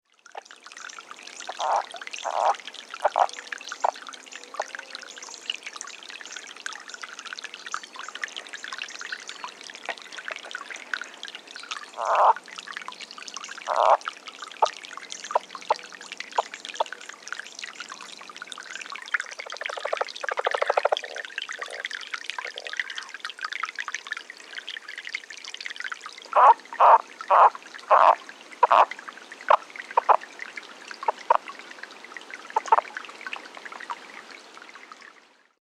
Lowland Leopard Frog - Lithobates yavapaiensis
The call of the Lowland Leopard Frog consists of several short quiet chuckles, sounding like quick, short, kisses.
The following recordings were made at dusk and at night at a small pool in a canyon bottom in Yavapai County, Arizona (shown below at dusk.) Water was slowly flowing into the pool. 3 or 4 frogs were calling.
Sound This is a 36 second edited recording of frogs calling at night. Bird noises are in the background.